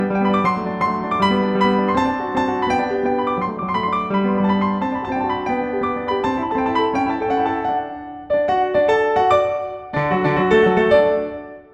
例えば第１楽章冒頭部のサビ、ノってきた感と共に、遊ぶように装飾音が連続しています。